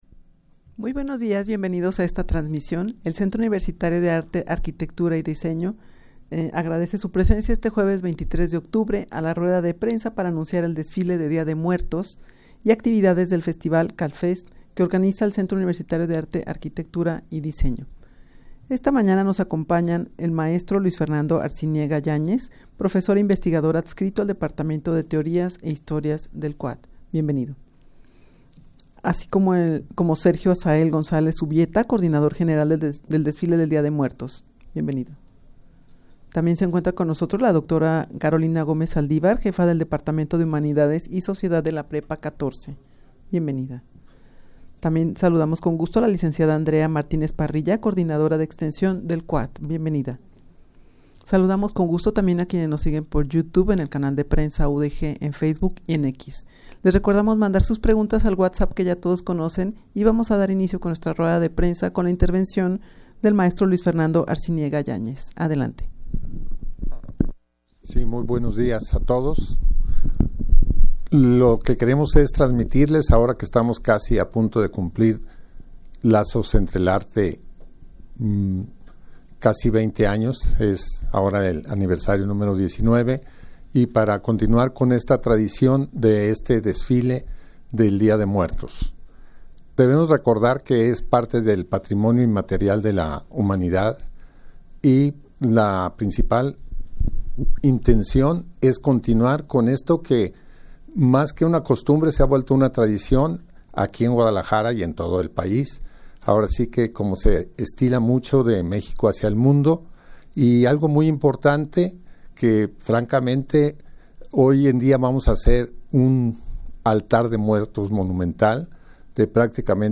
rueda-de-prensa-para-dar-a-conocer-el-desfile-de-dia-de-muertos-y-actividades-del-festival-calfest.mp3